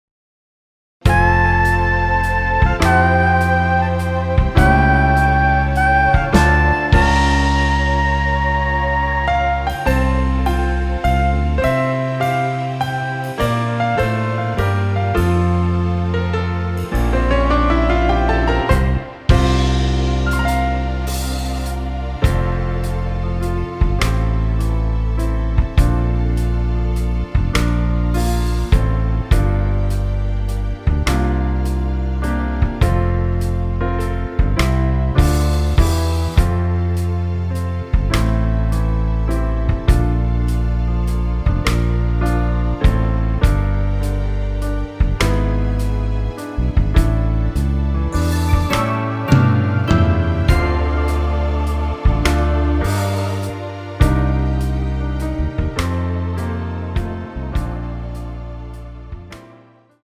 ◈ 곡명 옆 (-1)은 반음 내림, (+1)은 반음 올림 입니다.
노래방에서 음정올림 내림 누른 숫자와 같습니다.
앞부분30초, 뒷부분30초씩 편집해서 올려 드리고 있습니다.